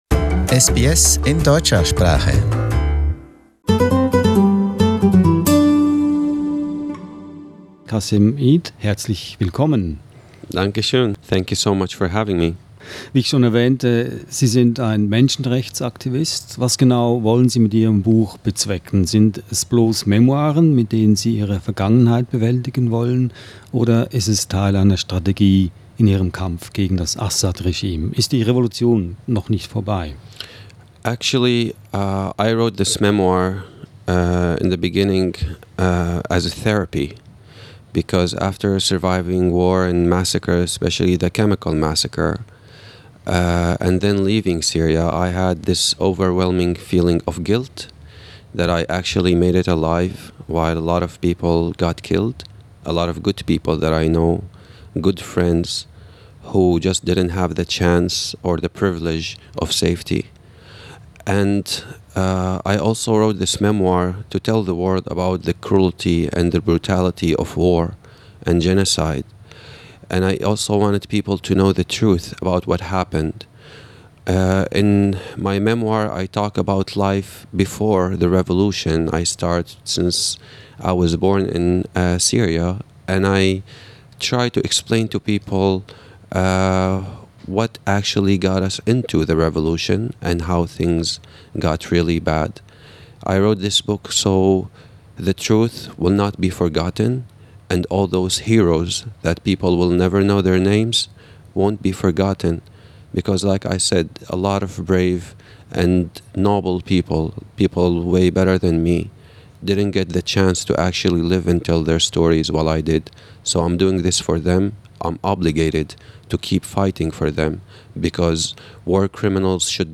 In the interview he speaks about his book, his fight against Assad’s regime, the right wing movement in Germany and Australia’s stand in handling refugees.